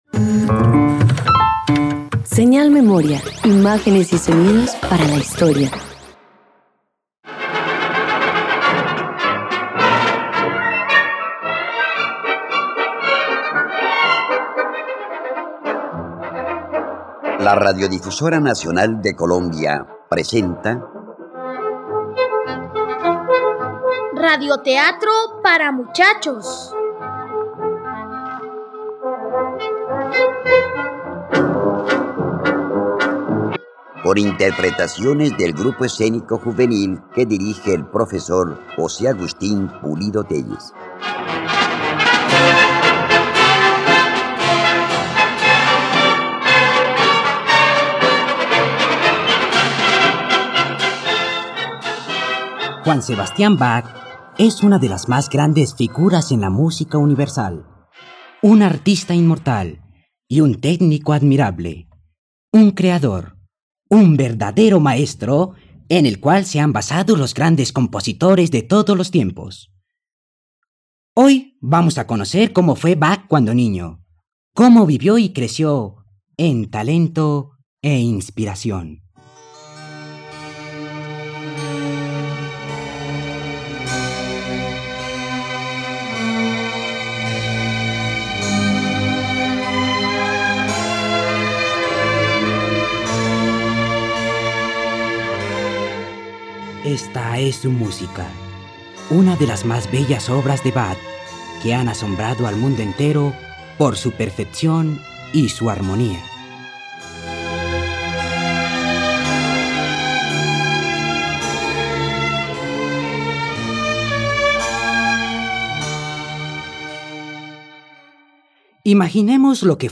radioteatro